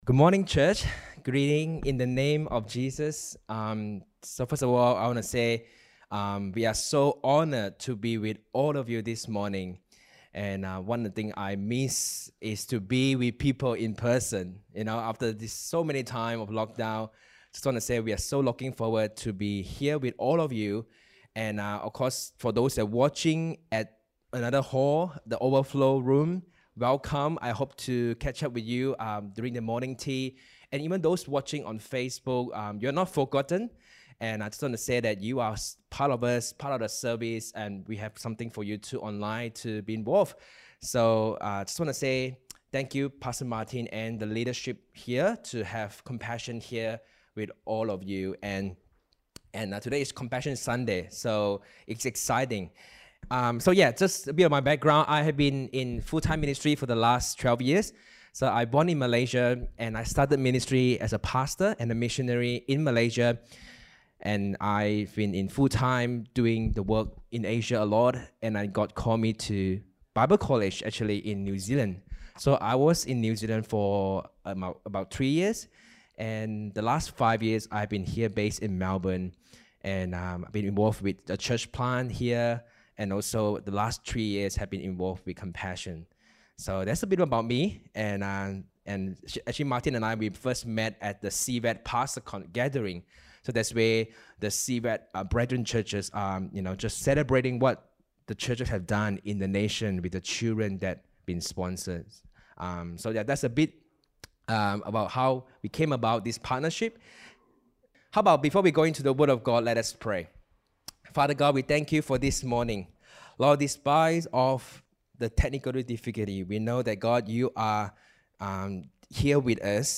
Topical Sermon
Service Type: Sunday Morning